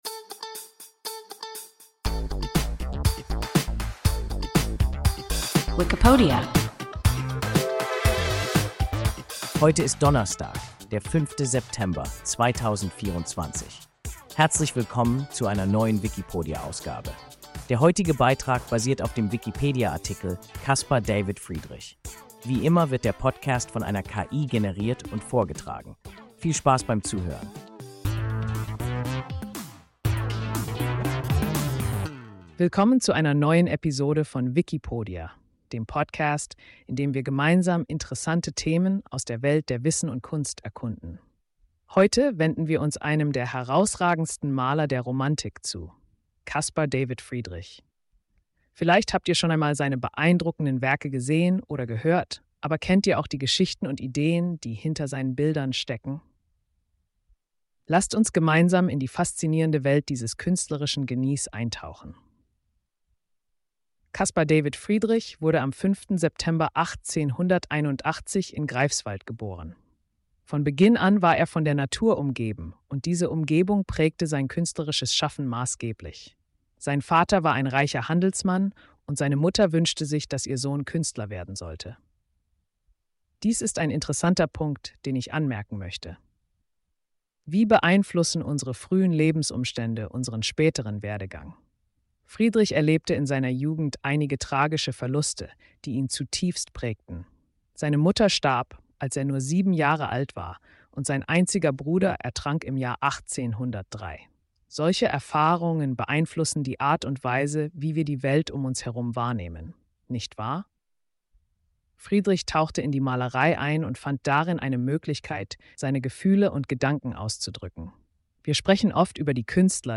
Caspar David Friedrich – WIKIPODIA – ein KI Podcast